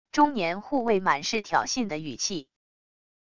中年护卫满是挑衅的语气wav音频